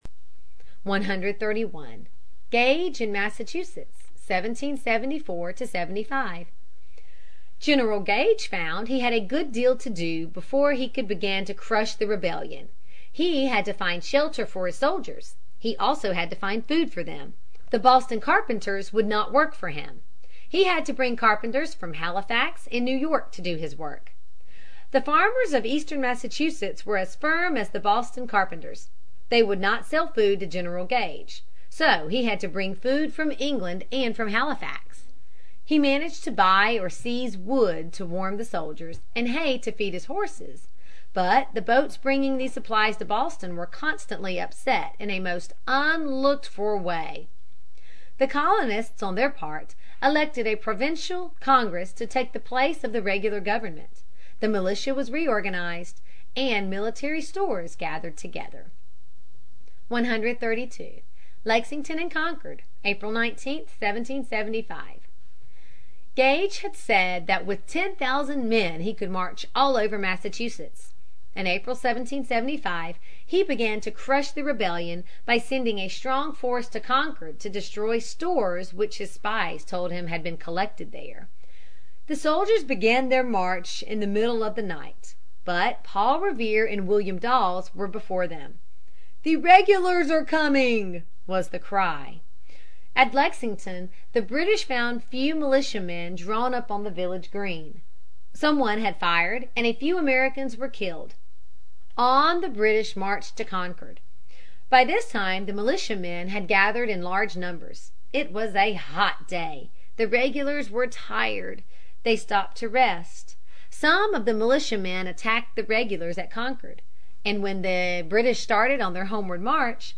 在线英语听力室美国学生历史 第43期:革命的的到来(5)的听力文件下载,这套书是一本很好的英语读本，采用双语形式，配合英文朗读，对提升英语水平一定更有帮助。